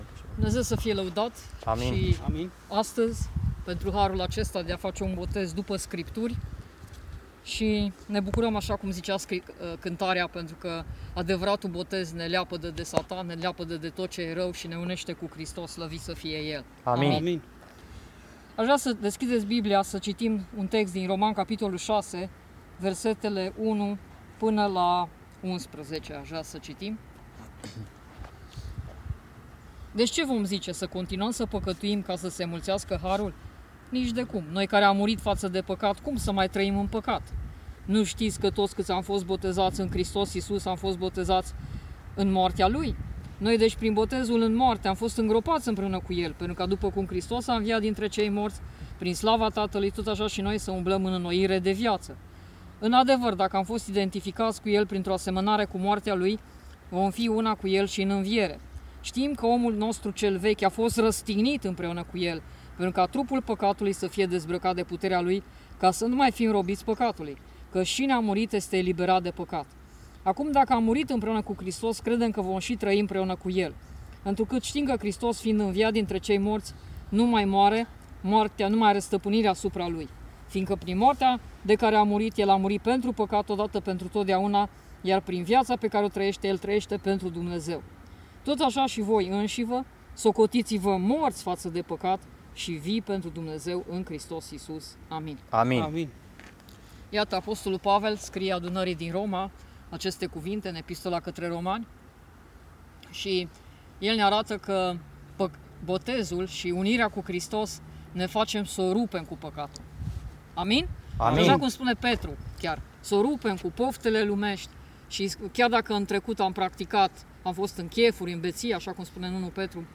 Predică înainte de Botez
Predica_inainte_de_Botez.mp3